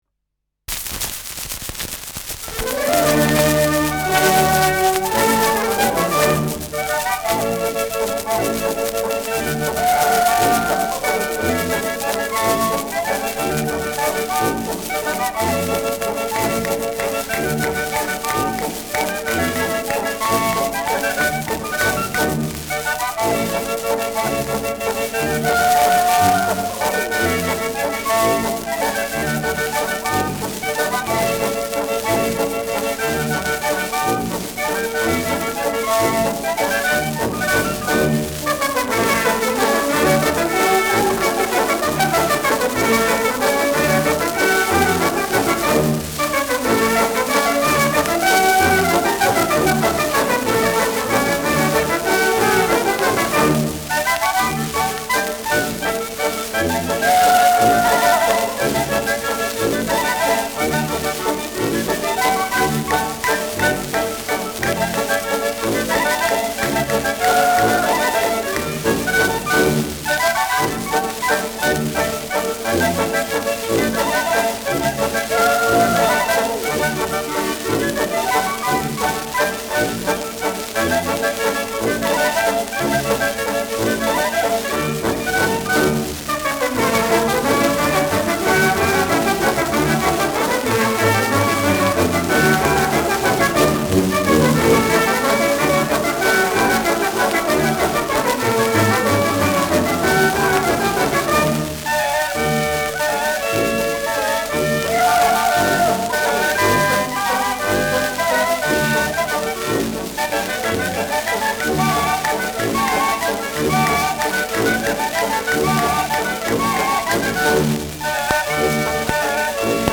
Schellackplatte
präsentes Rauschen
Mit Juchzern.
[Berlin] (Aufnahmeort)